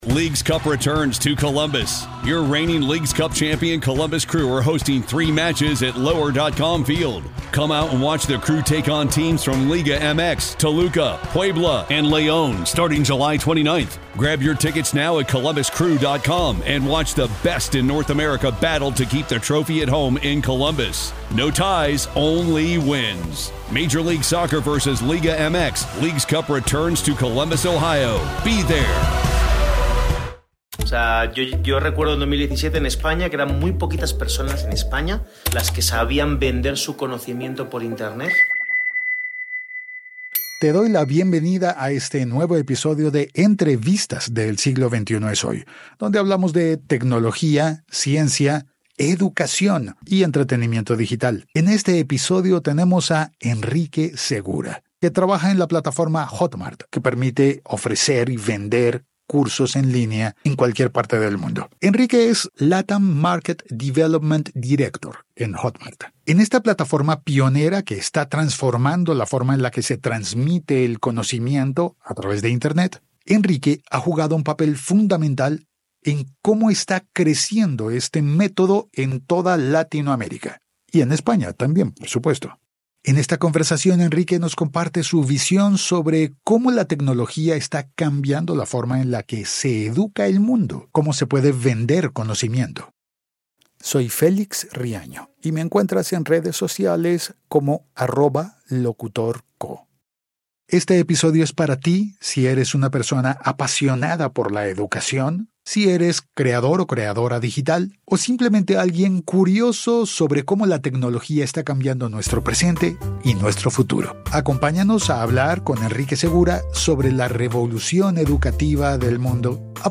Entrevistas del Archivo